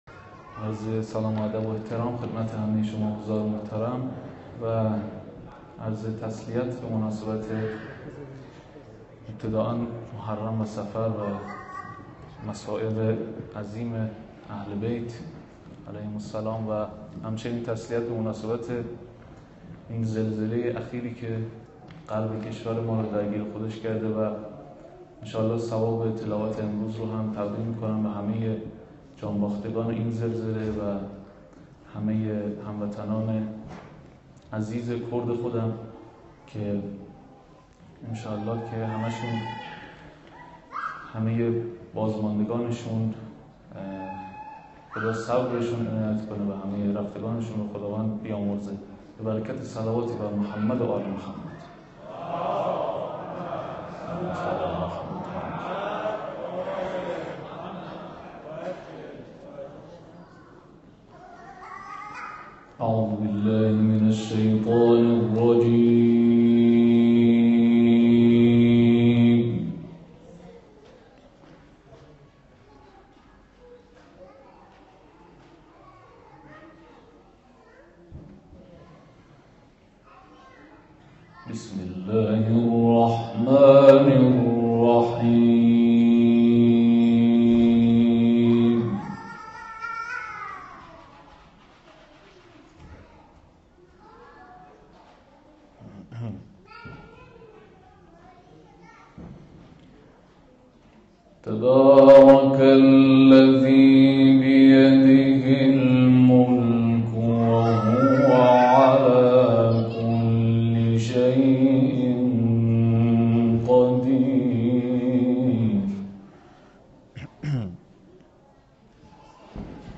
اهدای ثواب تلاوت به جان‌باختگان زلزله غرب کشور